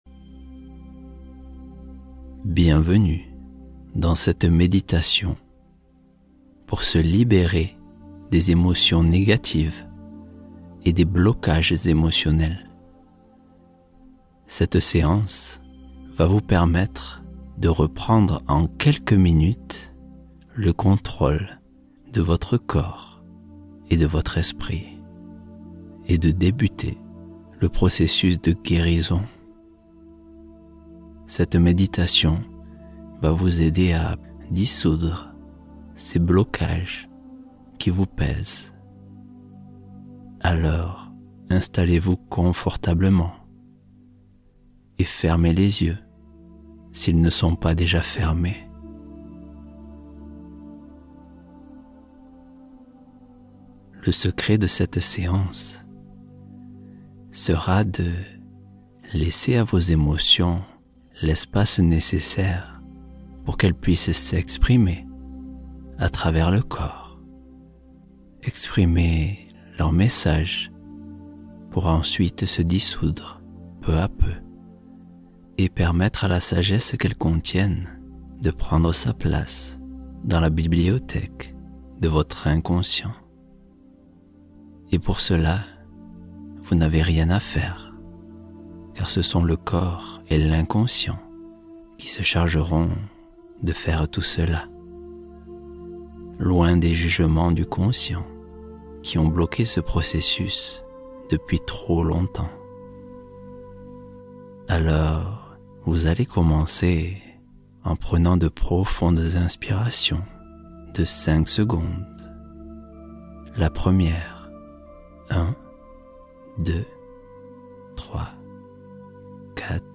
Accélère la manifestation de tes rêves : méditation guidée